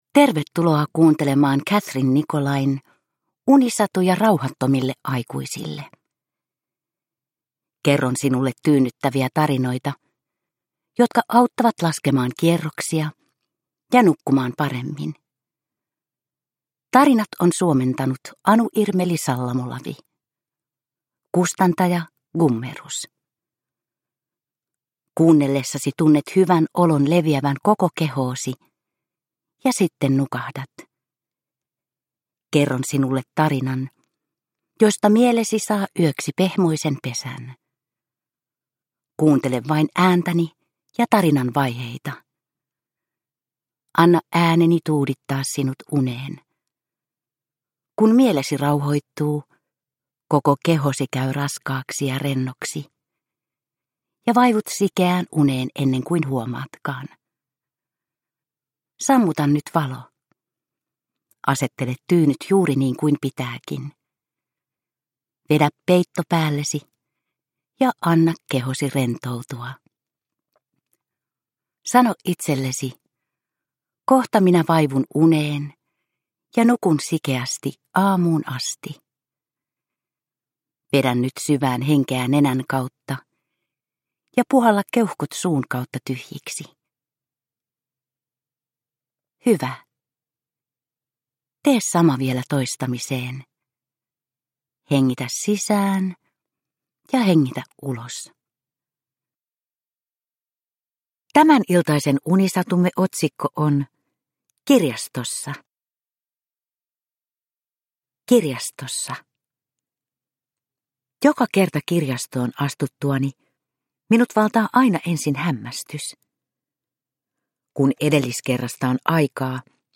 Unisatuja rauhattomille aikuisille 49 - Kirjastossa – Ljudbok – Laddas ner